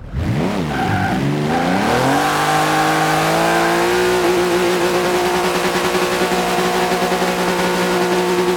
Index of /server/sound/vehicles/sgmcars/997
rev.wav